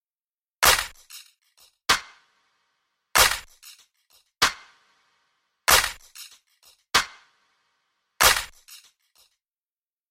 Tag: 95 bpm Electronic Loops Drum Loops 1.70 MB wav Key : Unknown